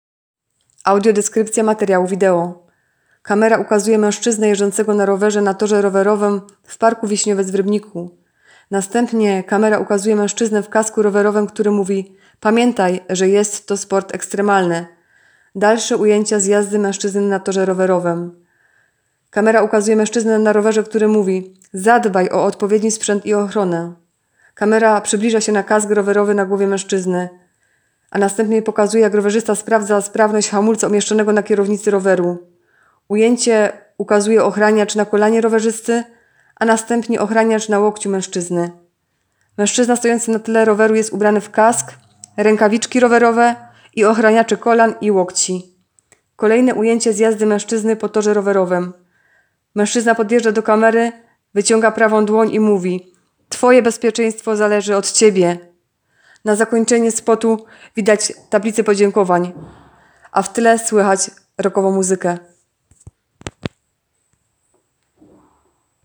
Opis nagrania: Audiodeskrypcja materiału wideo.